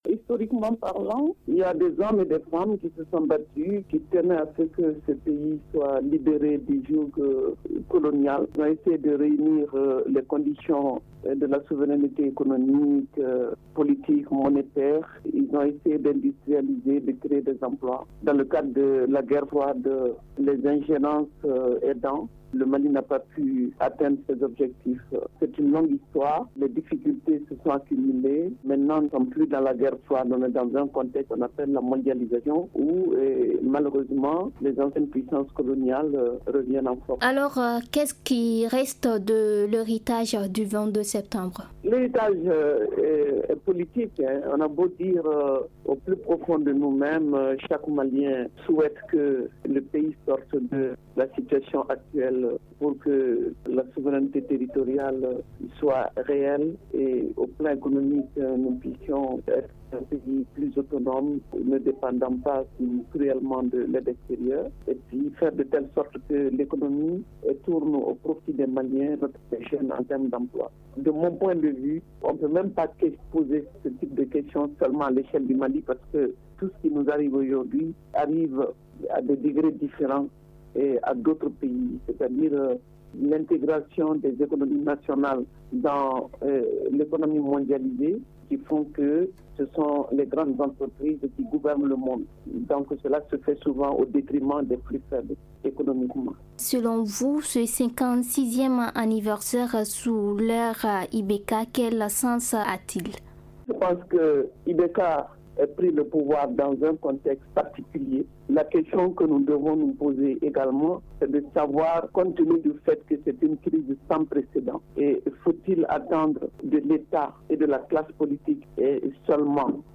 Elle est joint au téléphone